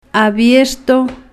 Phonological Representation a'bieɾʃto